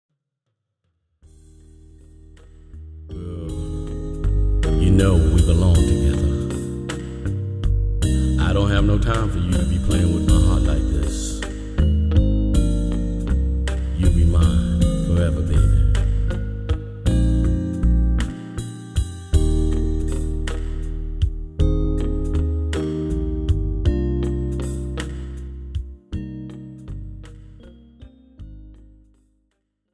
Pop , R&B , Soul